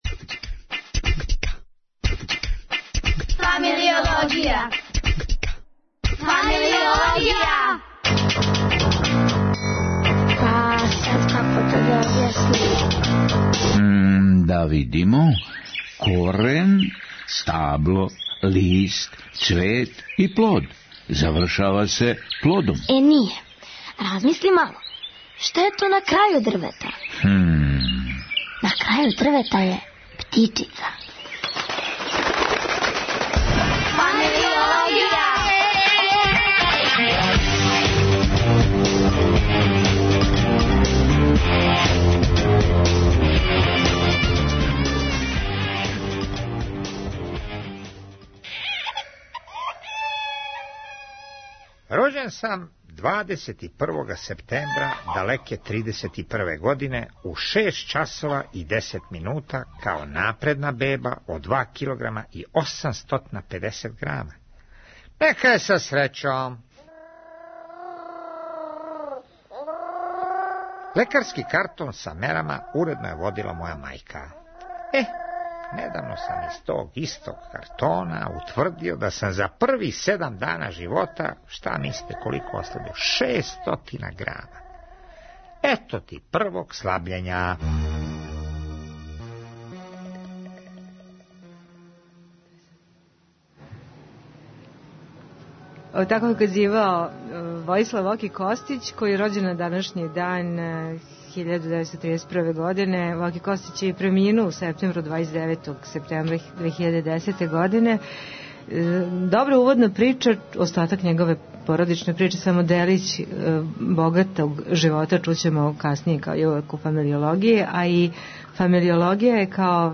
Данашња емисија се из Врбаса и емитује